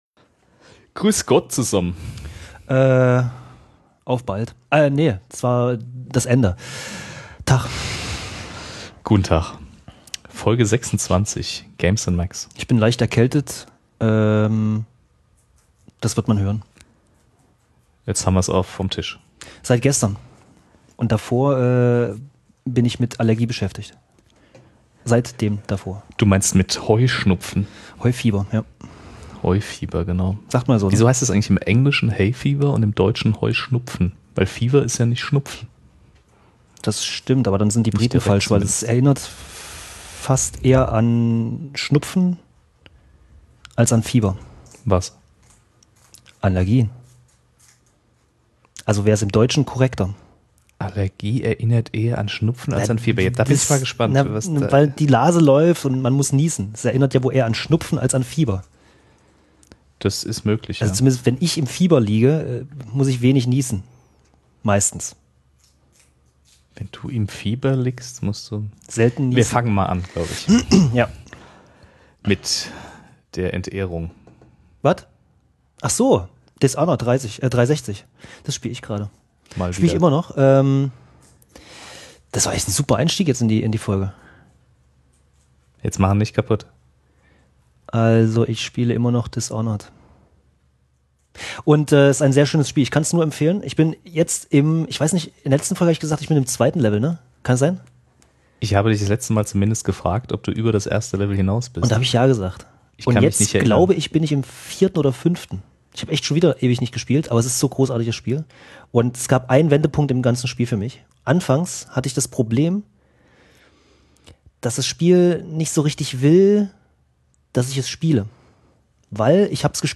Voller Heuschnupfen. Dafür dieses Mal mit extra vielen Hardwarethemen.